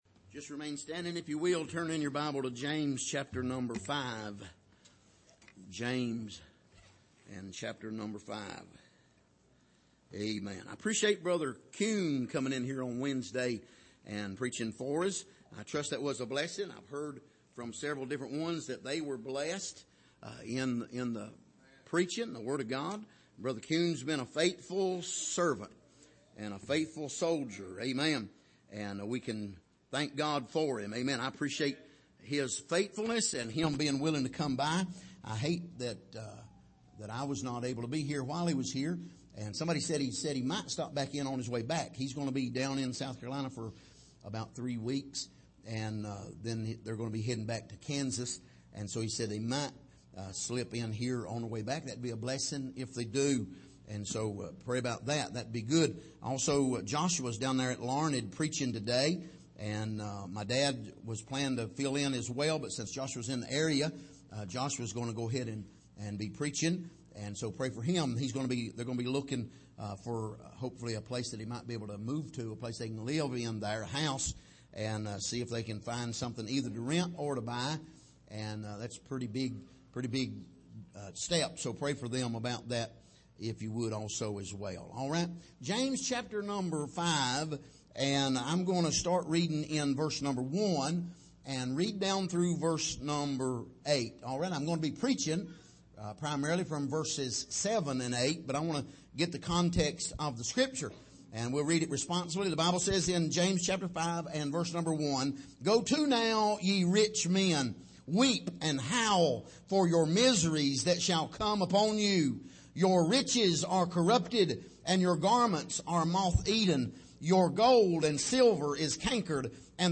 Passage: James 5:1-8 Service: Sunday Morning